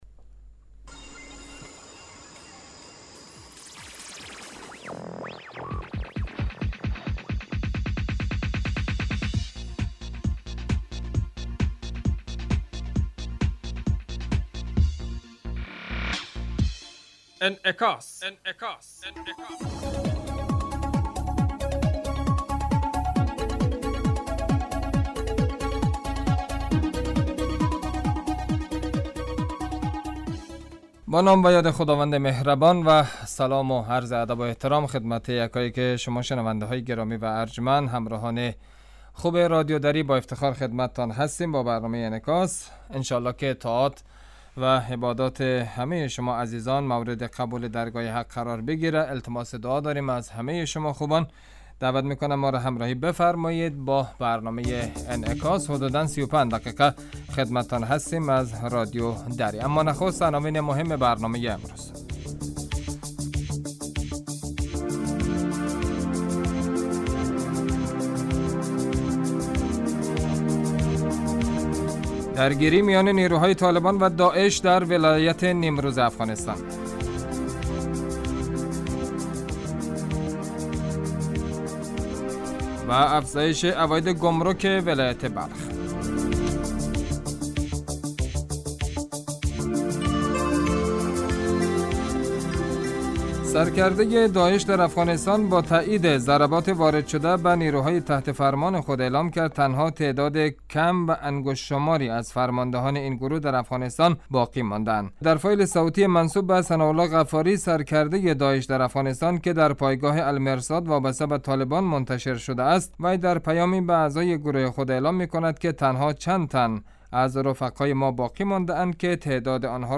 برنامه انعکاس به مدت 30 دقیقه هر روز در ساعت 05:55 بعد ظهر بصورت زنده پخش می شود.